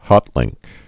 (hŏtlĭngk)